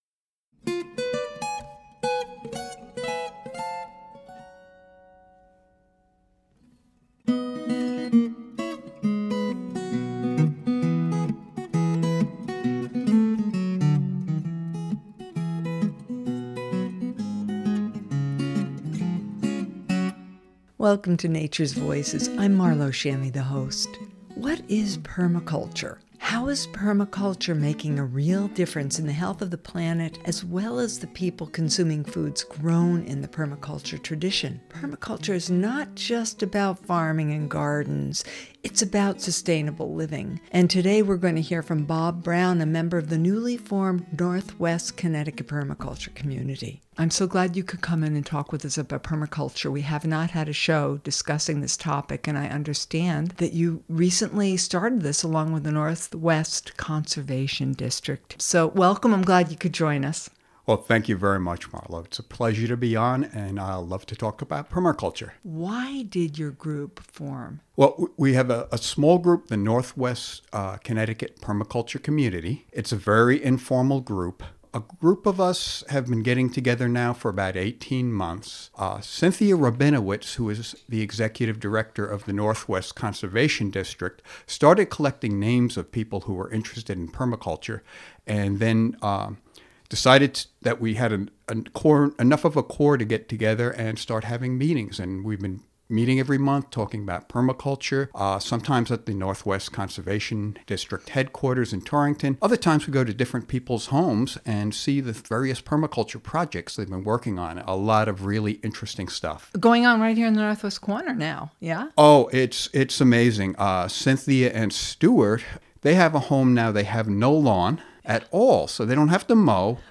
I had the pleasure of appearing on White Memorial’s Natures Voices radio show